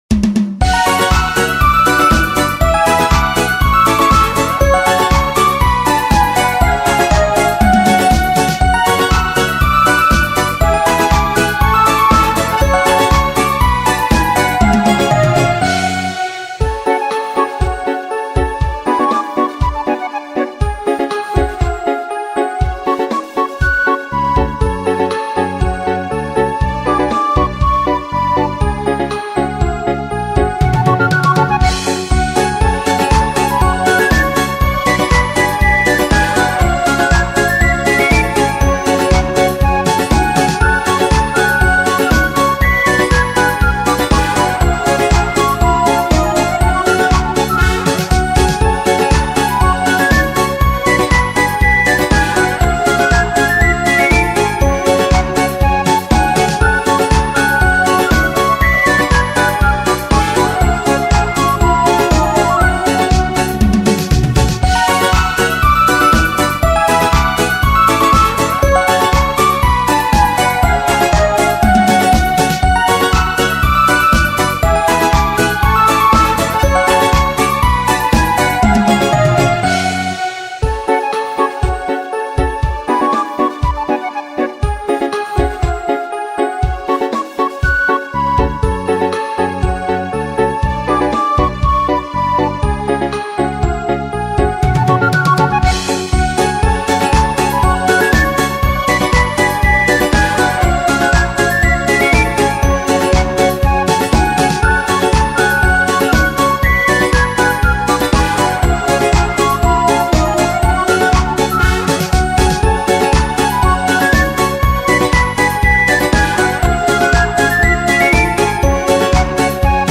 Букварик Буквар дитяча пісня
Букварик Буквар дитяча пісня (мінус).mp3